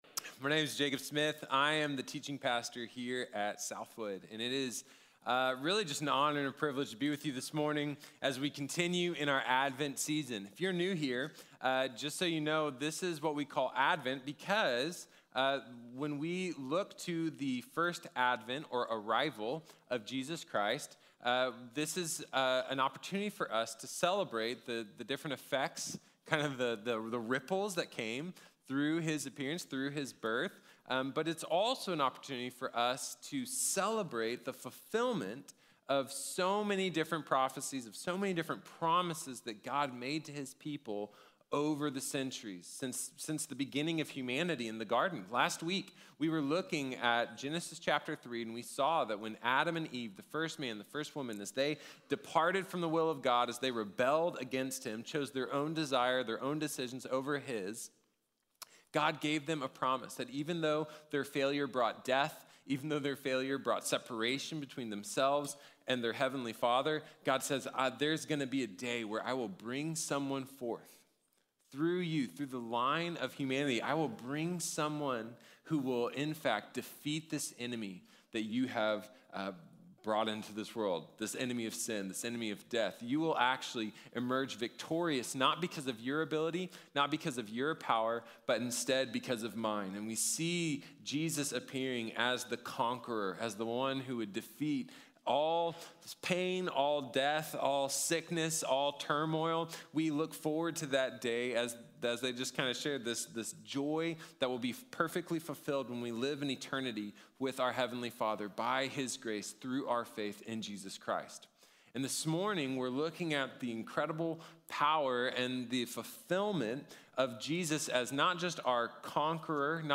Jesus Saves | Sermon | Grace Bible Church